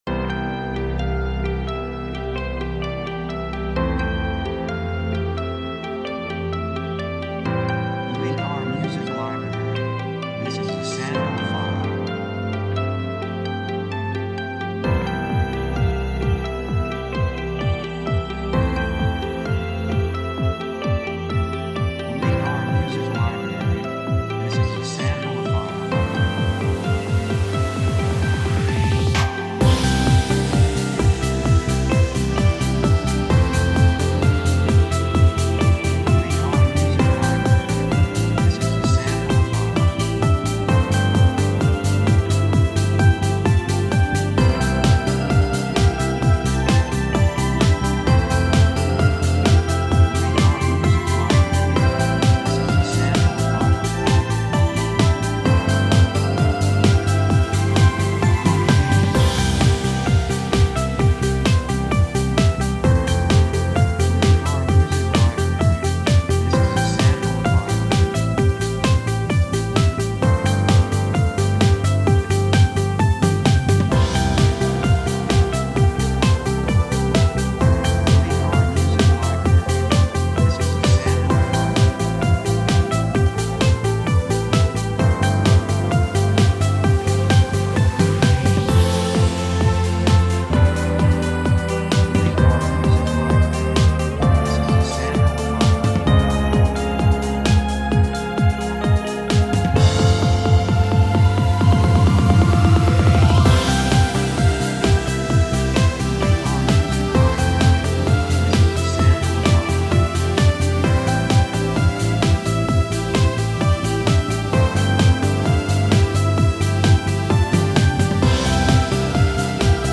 雰囲気幸せ, 高揚感, 決意, 喜び
曲調ポジティブ
楽器エレキギター, シンセサイザー, 手拍子
サブジャンルドラマ, ポップロック
テンポ速い
3:37 130 プロモ, エレクトロニック, スコア